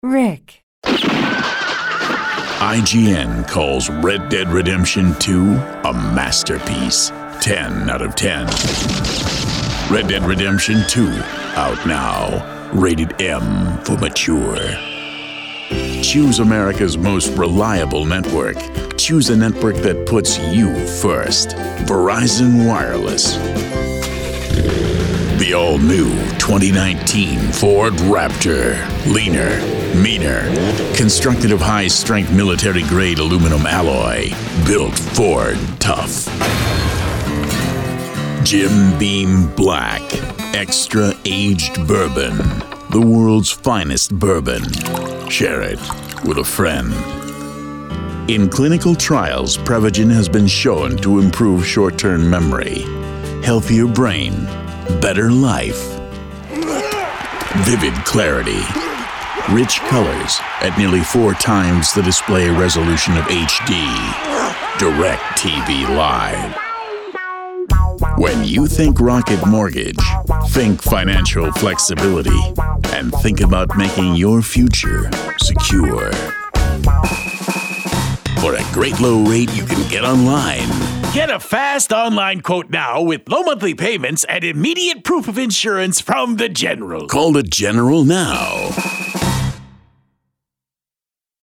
Showcase Demo
• TV Promos
announcer, attitude, authoritative, Booming, Deep Voice, edgy, Gravitas, mature, movie-trailer, ominous, promo, serious
accented, cartoon, character, conversational, crazy, kooky, oddball
biting, character, Deep Voice, edgy, gravelly, mature, tough